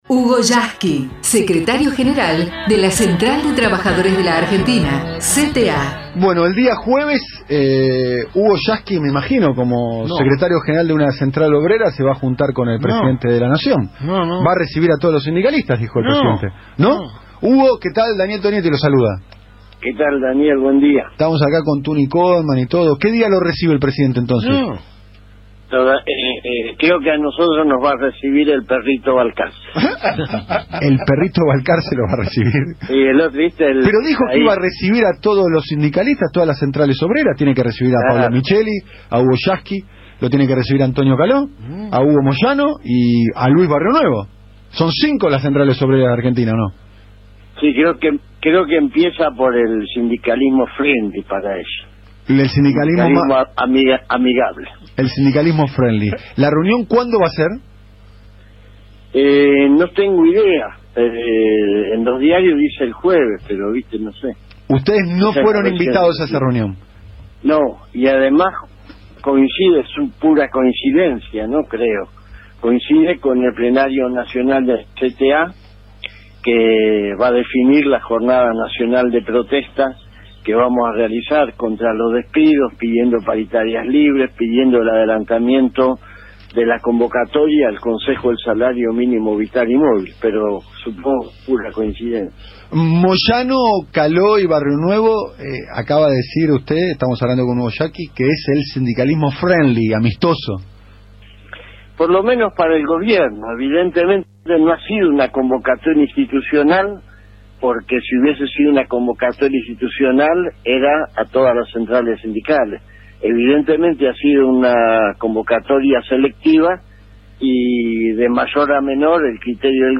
Nuestro Secretario General de la Central de Trabajadores de la Argentina entrevistado por el programa “Siempre es Hoy” conducido por Daniel Tognetti // Radio Del Plata (am1030), lunes a viernes de 09:00 a 12:00.